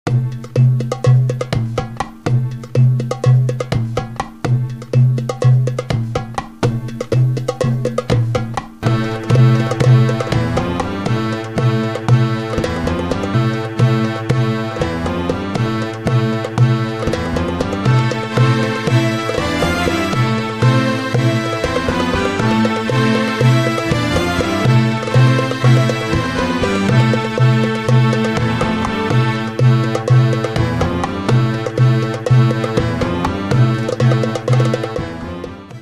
DERE DERE 9/8 - Med tempo 9/8